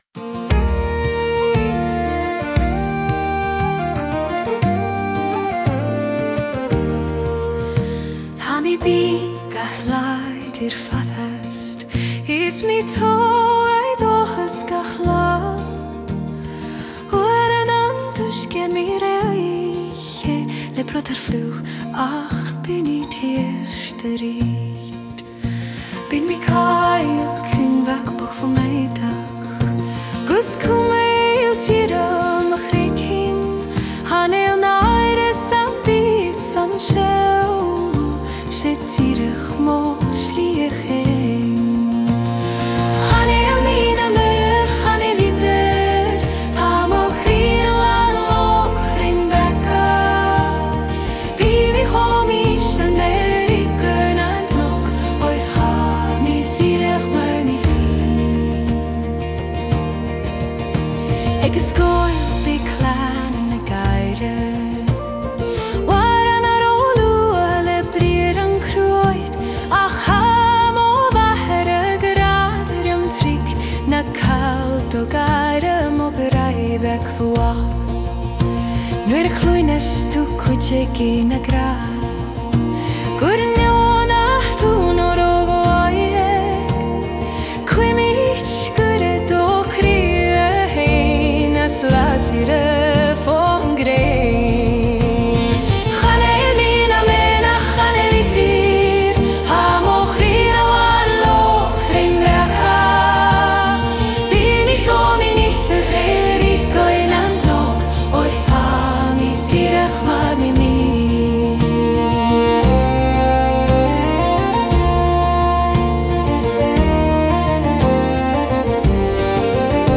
Genre: Irish Folk